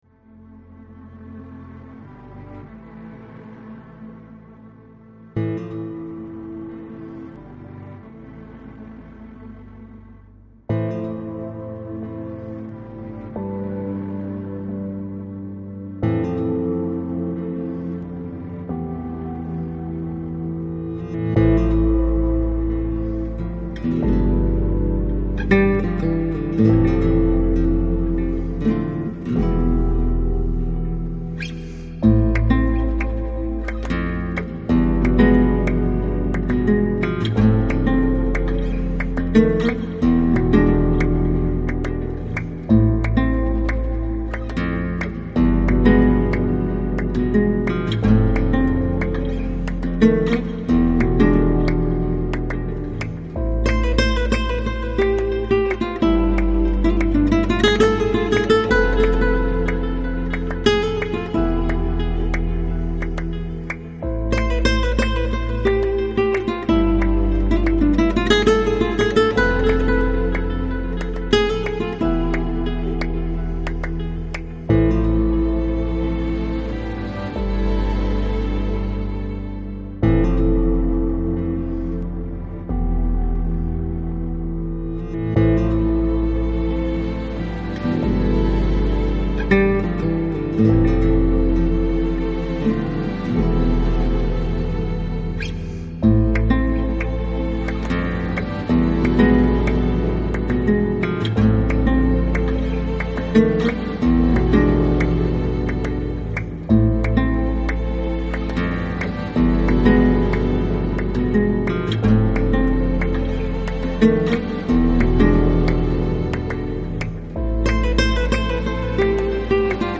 Lounge, Chill Out